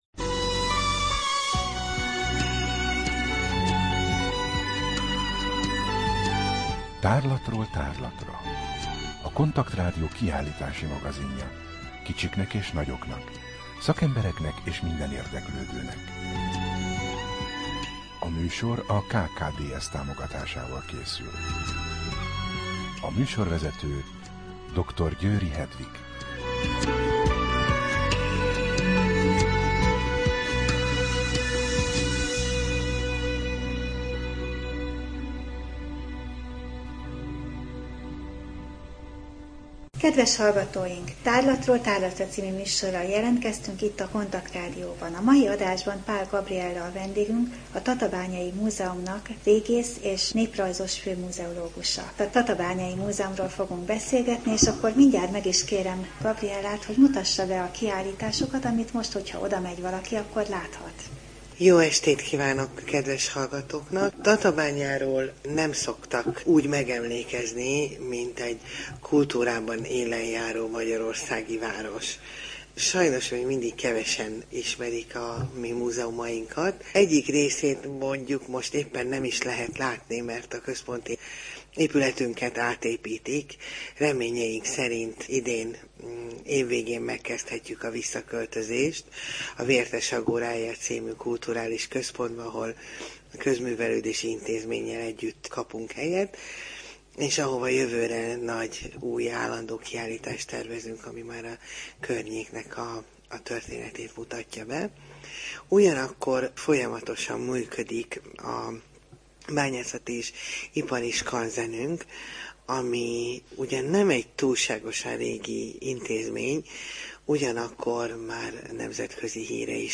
Rádió: Tárlatról tárlatra Adás dátuma: 2012, Október 1 Tárlatról tárlatra / KONTAKT Rádió (87,6 MHz) 2012. október 1. A műsor felépítése: I. Kaleidoszkóp / kiállítási hírek II. Bemutatjuk / Tatabánya, Bányászati Múzeum és Ipari Skanzen A műsor vendége